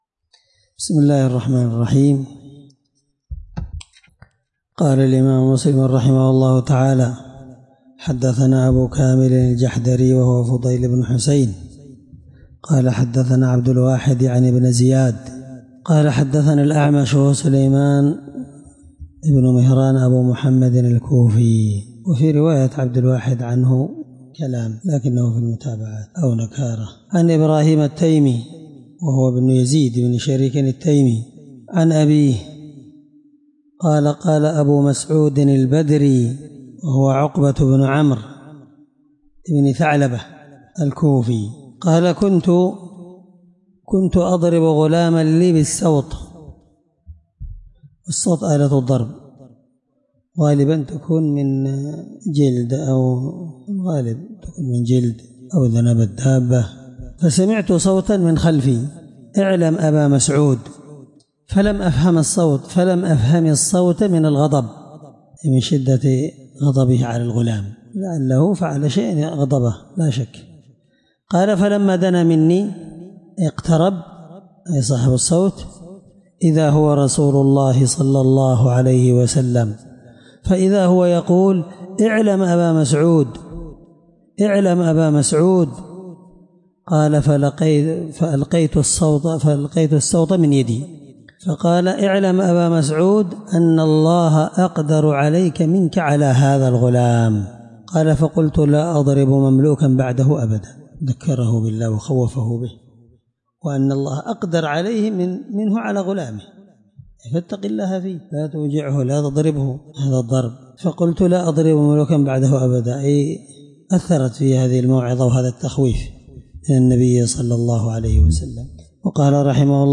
الدرس11من شرح كتاب الأيمان حديث رقم(1659) من صحيح مسلم